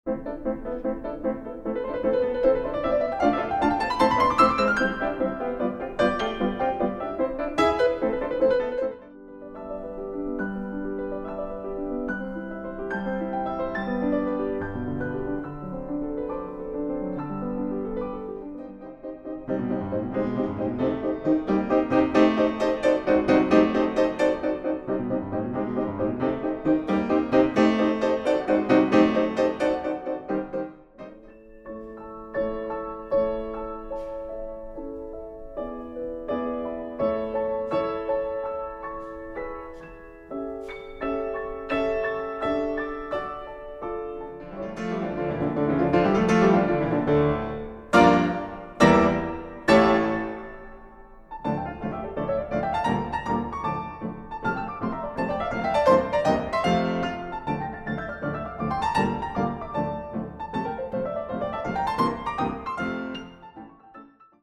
Two-piano recording